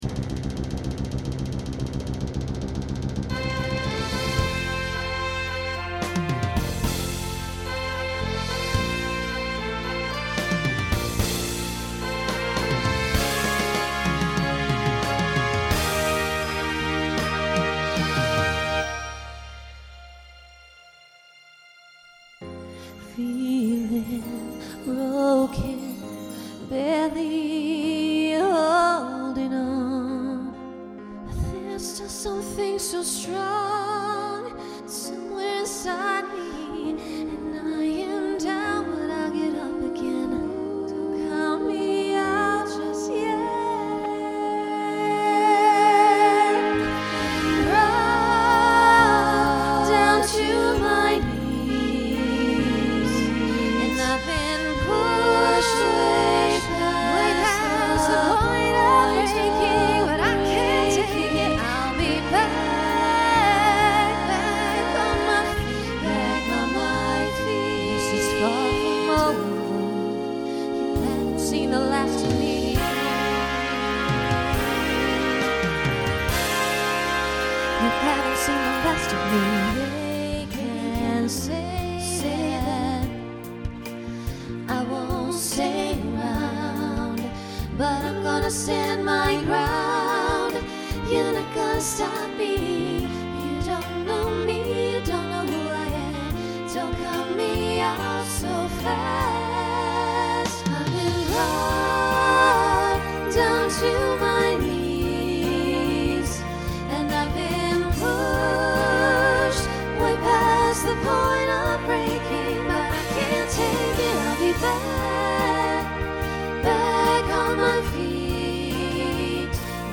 Voicing SSA Instrumental combo Genre Pop/Dance
Show Function Ballad